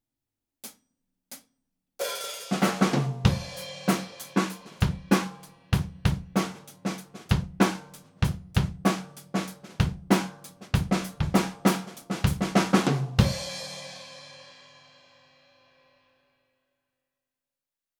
ドラムセットは、Canopus　yaiba kit
すべて、EQはしていません。
①アンビエンス　約1m
まずは、遠目で録っていきます！
遠目で鳴ってる感ありますね！
これぞアンビエンスですね！
ドラム-アンビ-1m.wav